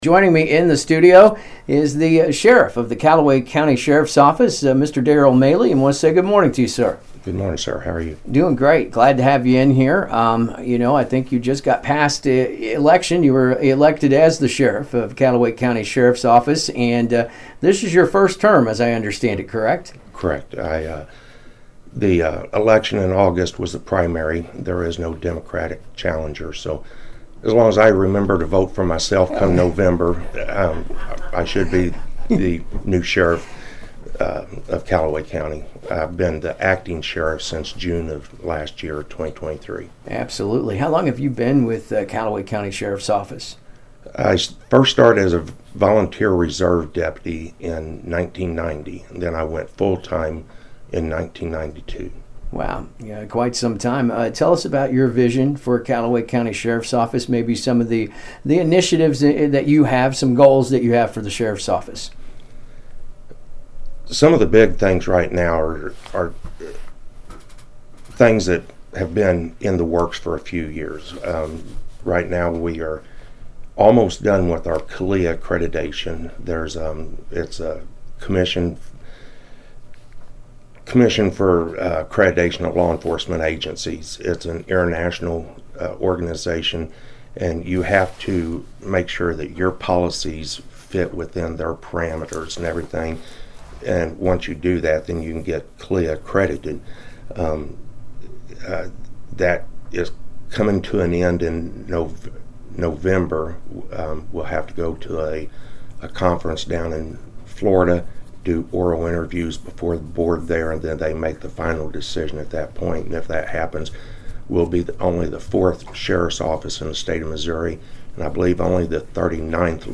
Callaway County Sheriff Darryl Maylee Joins AM 1340 KXEO’s Am I Awake Morning Show
sheriff-mayley-int.mp3